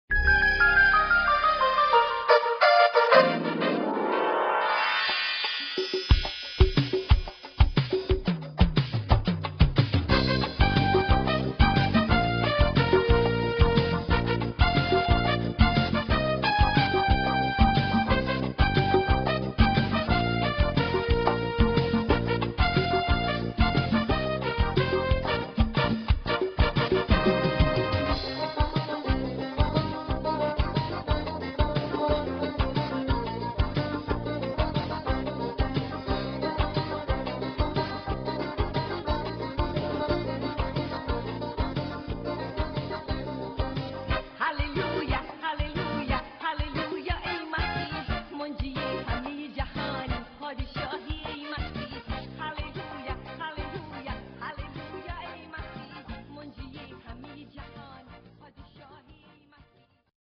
POP Persian (Farsi) Christian Music